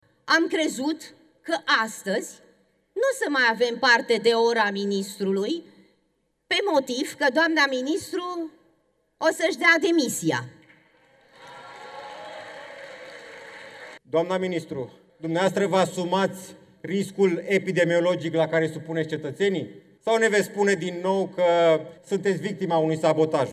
Opoziția și, nu numai, i-au cerut, însă, demisia: Grațiela Gavrilescu, deputat PSD, urmată de Cristian Câmpan, deputat de la Partidul Oamenilor Liberi.